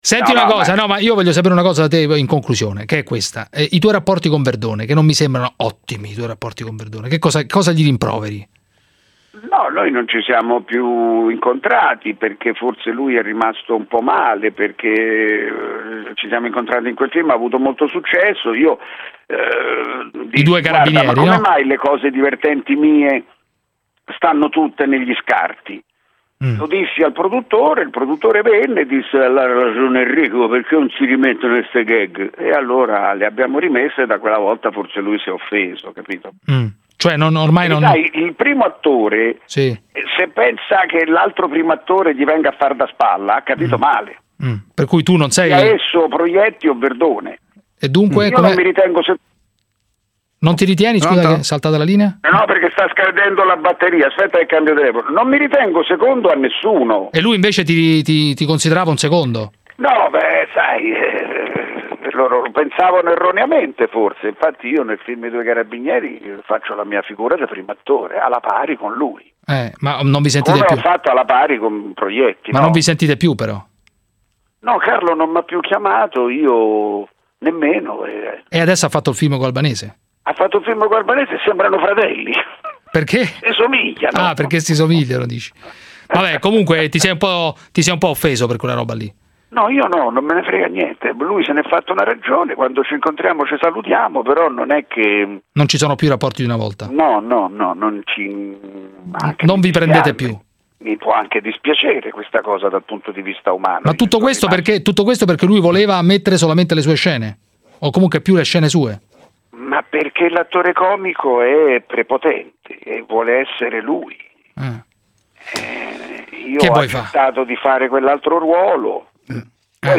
• In un’intervista del 2016 a La Zanzara su Radio 24, Enrico Montesano spiegò gli screzi avuti con Carlo Verdone riguardo il film, che portarono i due attori a non avere dei buoni rapporti.